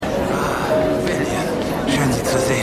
Synchronstudio: Iyuno Germany GmbH [Berlin]